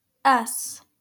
Its name in English is ess[a] (pronounced /ˈɛs/